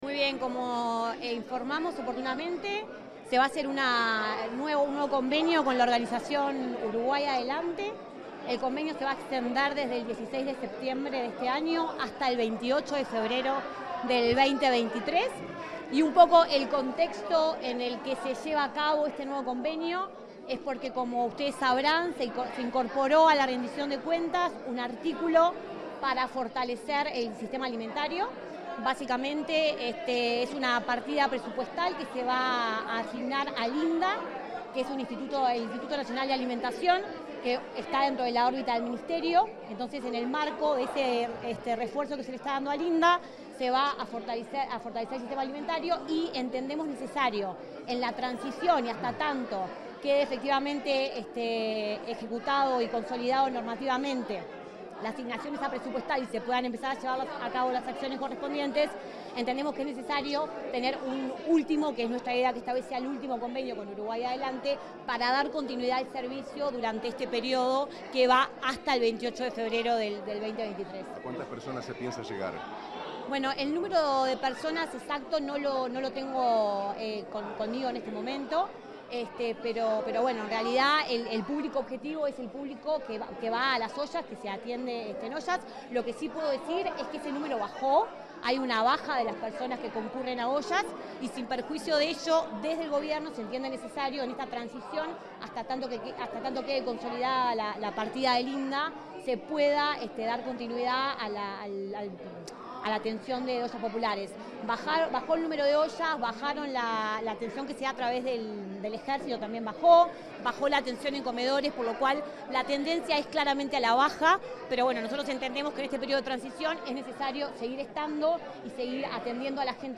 Declaraciones de la directora general del Mides
La directora general del Mides, Karina Goday, informó a la prensa sobre la extensión del convenio con la organización Uruguay Adelante.